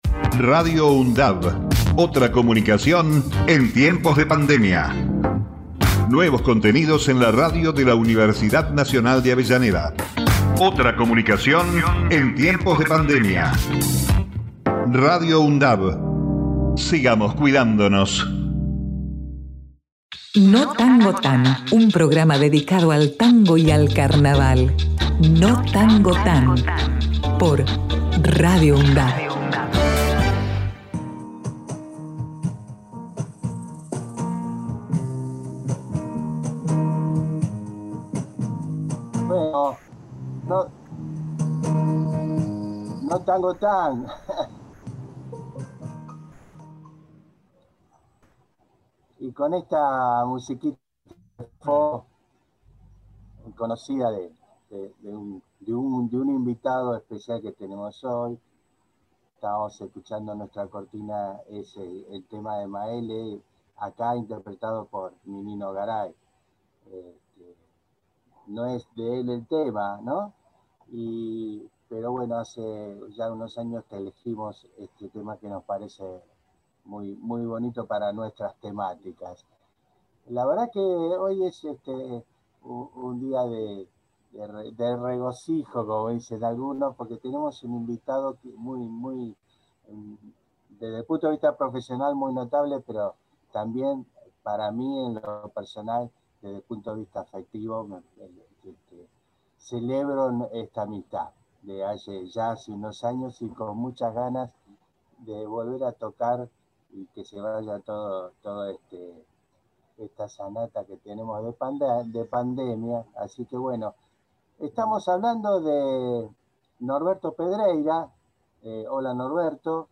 guitarrista y compositor